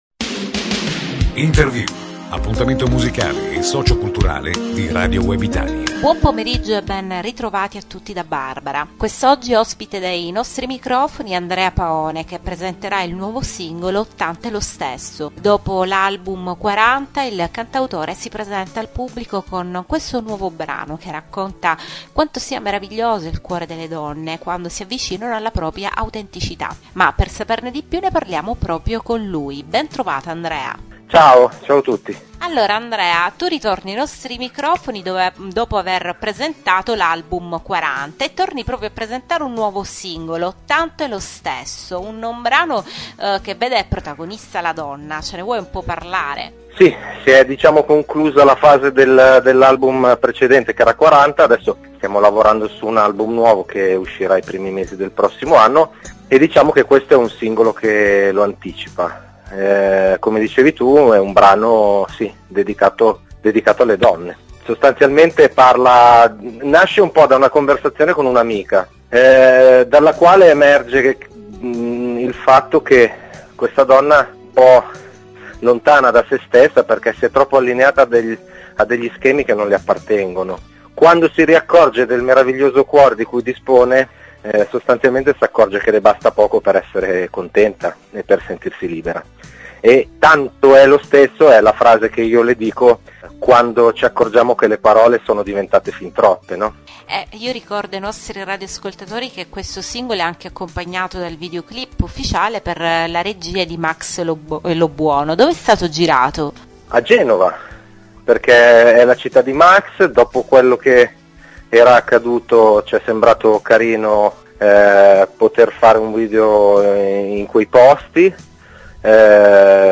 Intervista On Air mercoledì 7 novembre ore 18.10